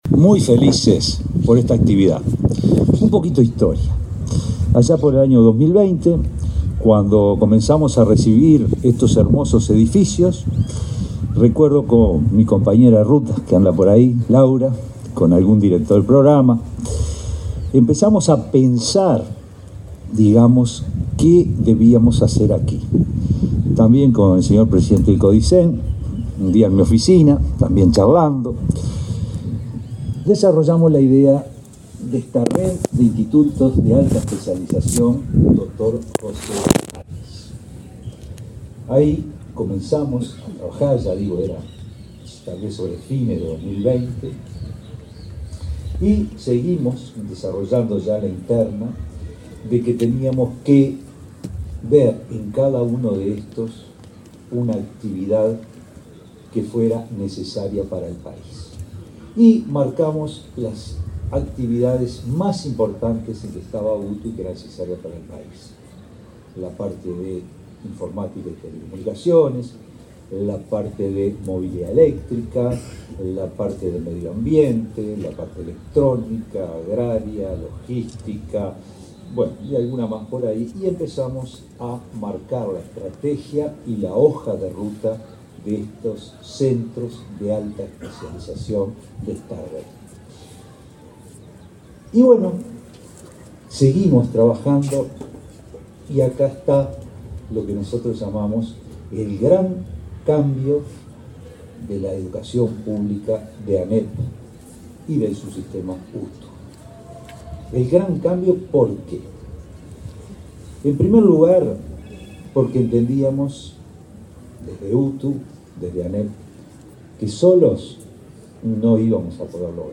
Entrevista al presidente de la ANEP, Robert Silva